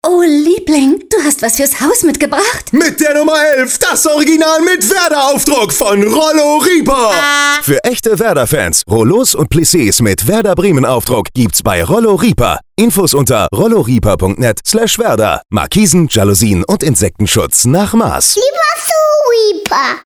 Seit letztem Montag laufen wieder neue Werbespots in Bremen’s meistgehörten Radiosendern Radio Bremen 1 (Hansewelle) und Bremen 4.
Diesmal werden über witzige Geschichten von zwei Eheleuten die neuen Werder Bremen Rollos beworben, die Rollo Rieper erst kürzlich im Programm aufgenommen hat.